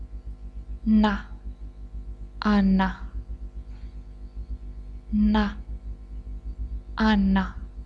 Consonnes - Sujet #1
n
n_na_ana1_[24b].wav